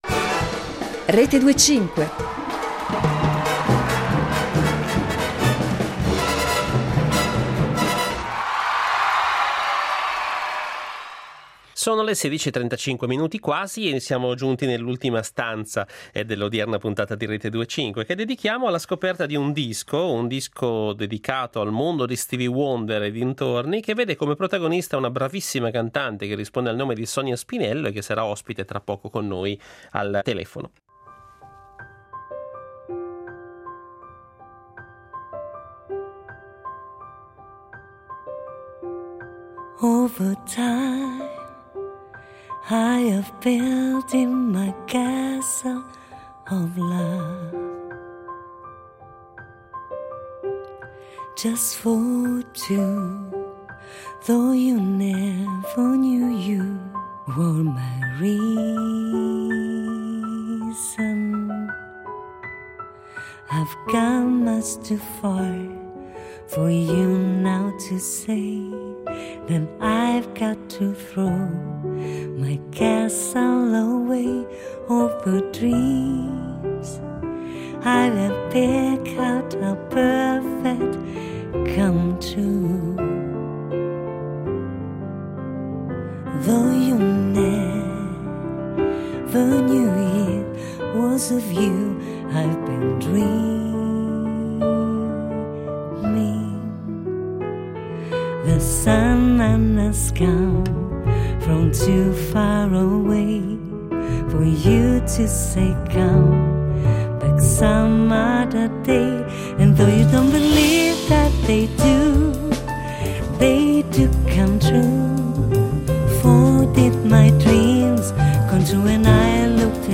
L'ascolto, reso suggestivo grazie all'essenzialità degli arrangiamenti dei suoi brani più struggenti e carichi di pathos, lascia spazio ai colori e alla leggerezza della voce. I brani sembrano essere sospesi in un gioco di equilibri risultando a tratti eterei. Il progetto propone anche composizioni inedite ispirate alla sua poetica.